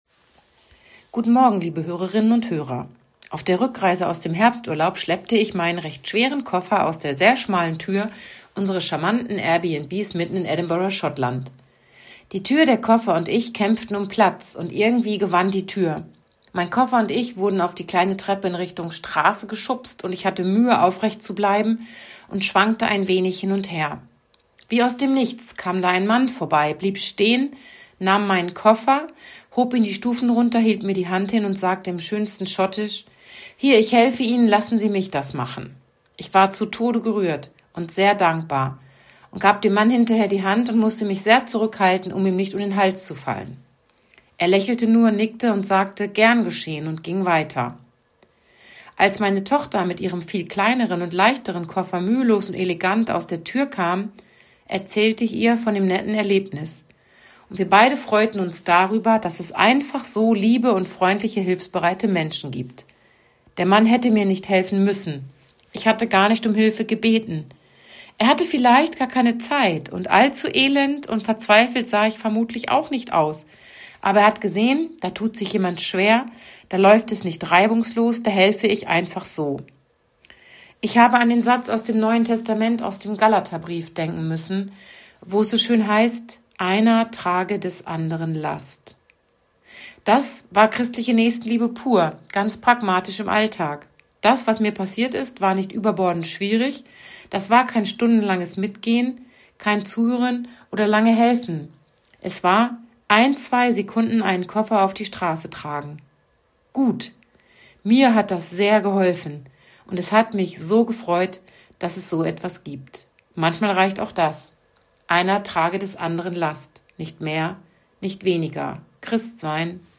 Radioandacht vom 22. Oktober